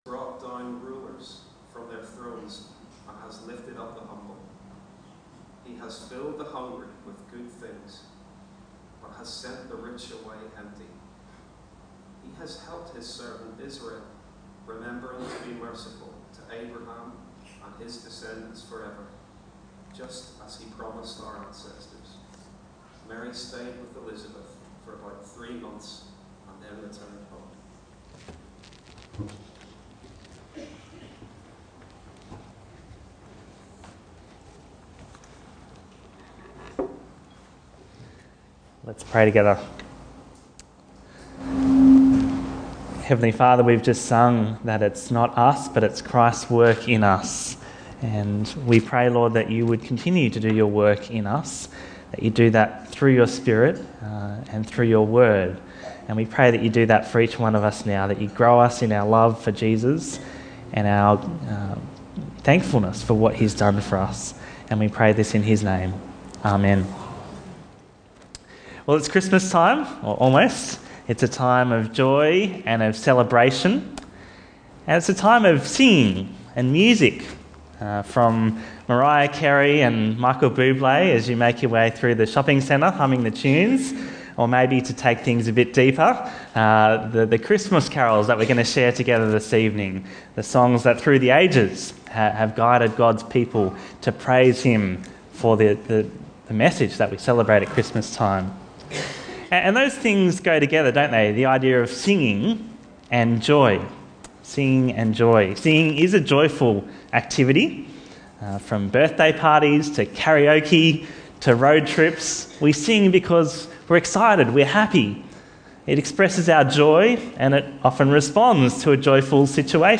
Bible Talks Bible Reading: Luke 1:39-56